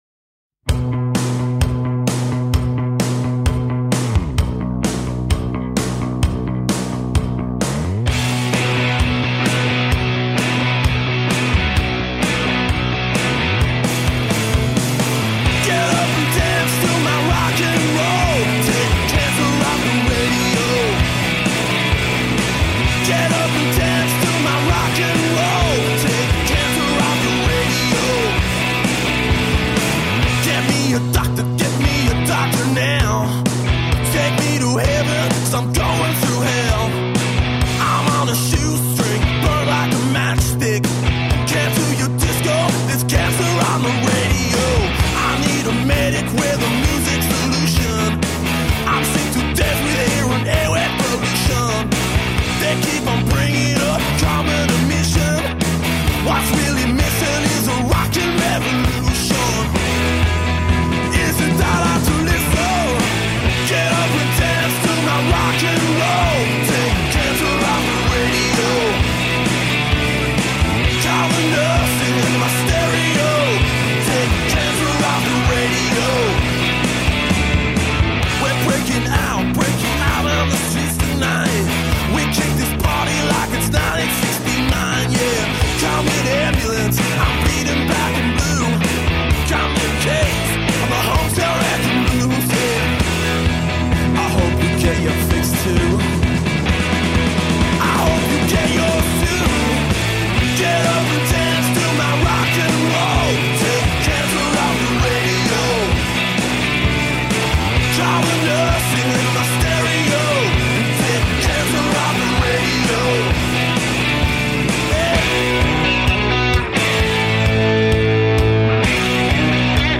Rock and roll from the heart.
Tagged as: Hard Rock, Punk